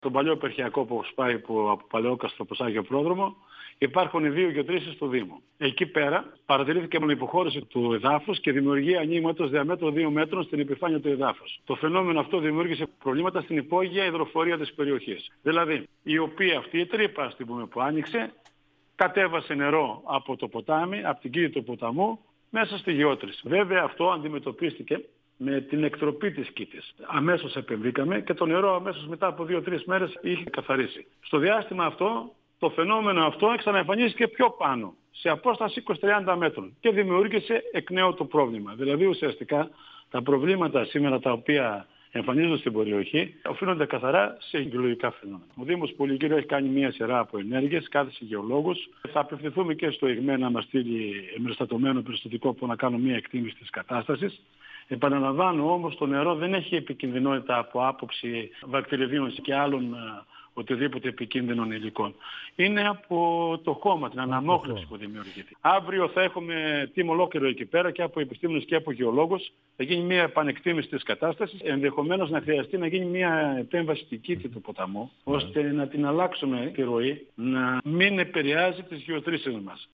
Ο αντιδήμαρχος Υδρευσης και Καθαριότητας του δήμου Πολυγύρου, Δημήτρης Ζαγγίλας,  στον 102FM του Ρ.Σ.Μ. της ΕΡΤ3
Συνέντευξη